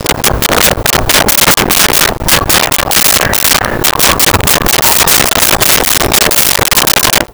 Chickens In Barn 03
Chickens in Barn 03.wav